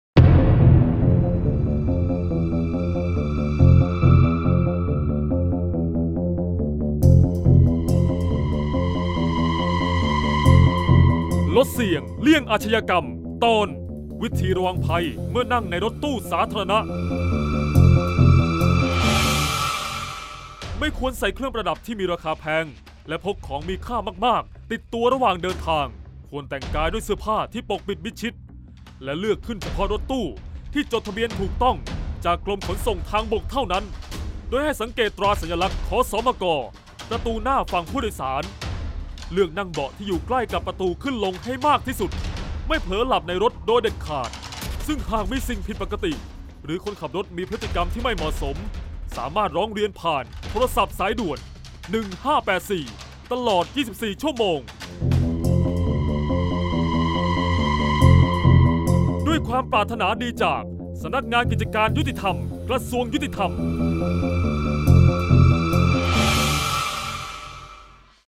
เสียงบรรยาย ลดเสี่ยงเลี่ยงอาชญากรรม 24-ระวังภัยเมื่อนั่งรถตู้